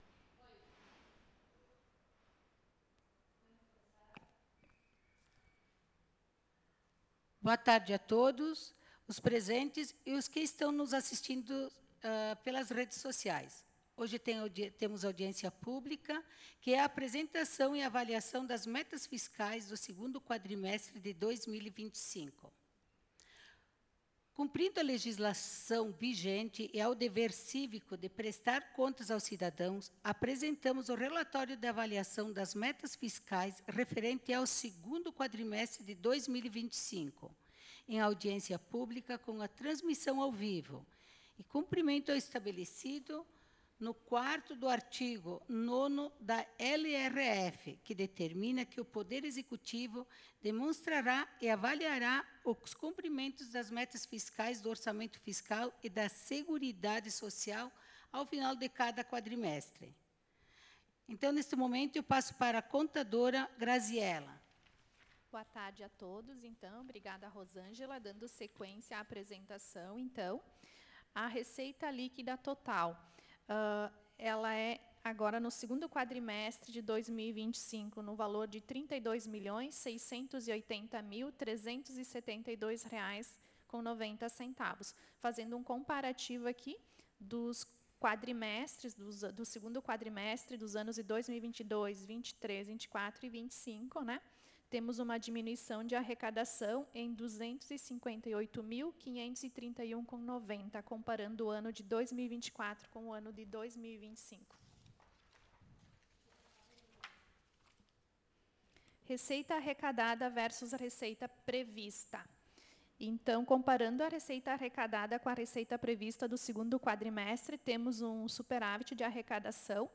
Aos vinte e três do mês de setembro de dois mil e vinte e cinco, às 14:00 horas, reuniram-se na Sala das Sessões da Câmara Municipal de Vereadores de Nova Roma do Sul os responsáveis pela realização da audiência pública de demonstração e Avaliação